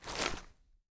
gear_rattle_weap_medium_09.ogg